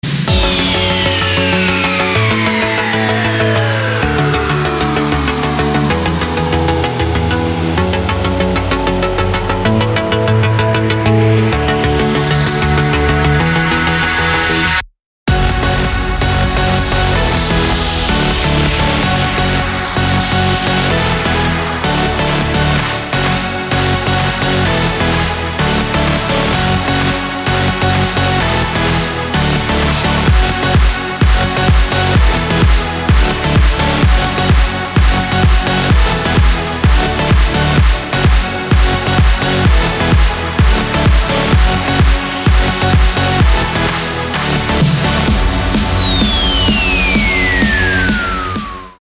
• Качество: 320, Stereo
громкие
dance
электронная музыка
без слов
club
house